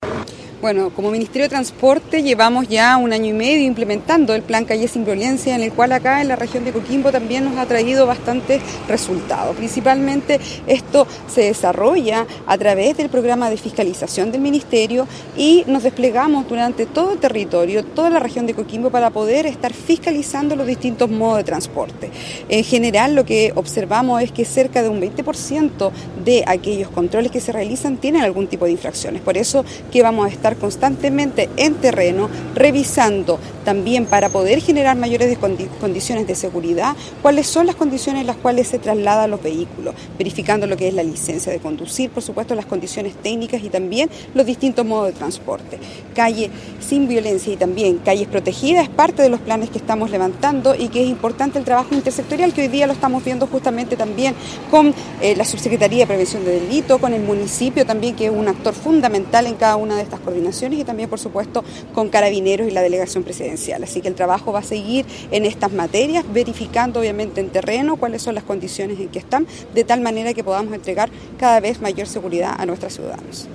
FISCALIZACION-VEHICULAR-Alejandra-Maureira-Seremi-de-Transportes.mp3